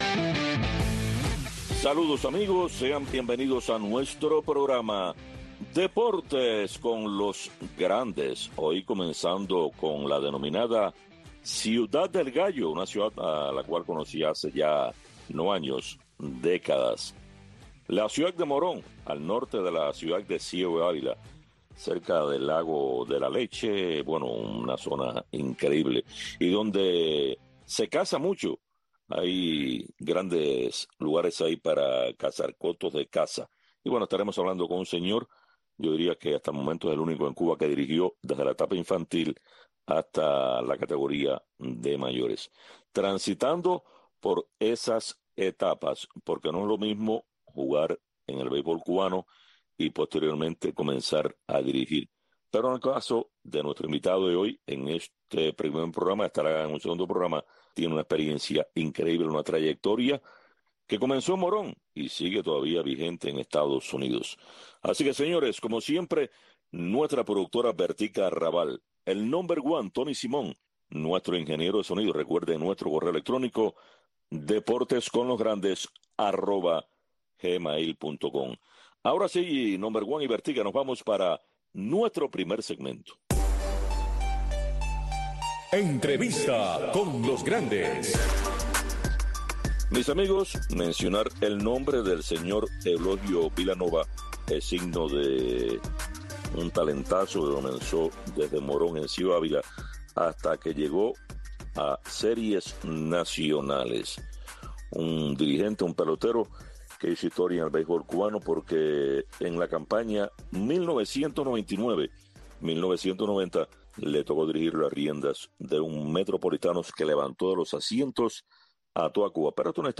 Deportes con los grandes. Un programa de Radio Marti, especializado en entrevistas, comentarios, análisis de los grandes del deporte.